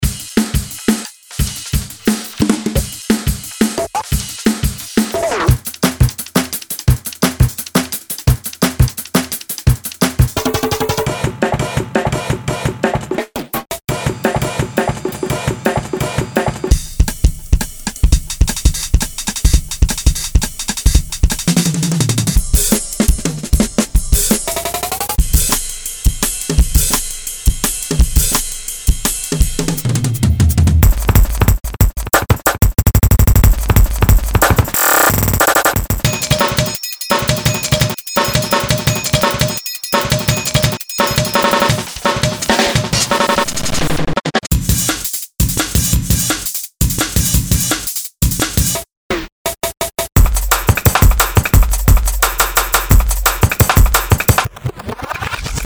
DnB Drum Loops Mega Pack
This Mega pack brings your our two most intense Dnb drum loops sample packs combined into one almighty bundle of electronic drum carnage.
With 391 Mb of hybridized drum loops that were hand crafted from some of our funkiest live acoustic drum breaks you’ll be literally spoilt for choice with these insane grooves and fills. From sublimely subtle to brash and brutal, this sample pack has all kinds of killer breaks, variations and fills to give your DnB tracks some top class intensity.
Tempos: 156 to 184 Bpm
drum_and_bass_v1.mp3